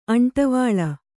♪ aṇṭavāḷa